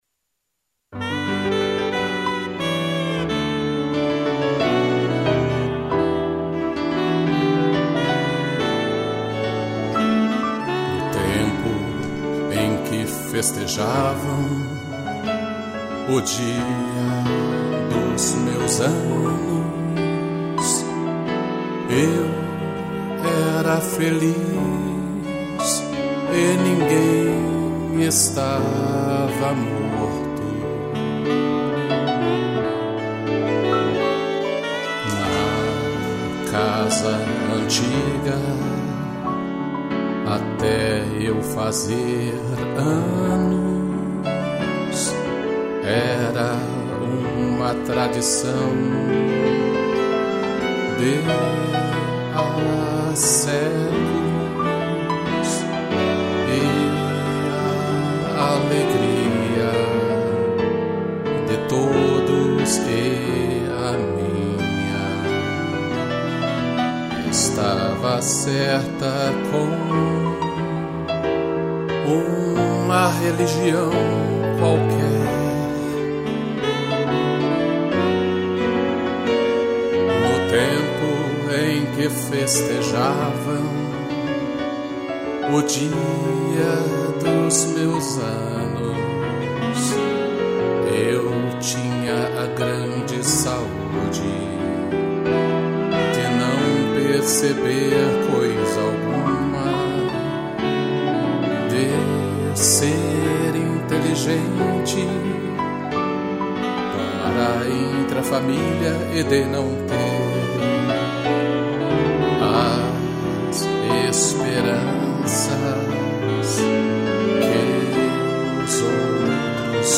2 pianos - strings e sax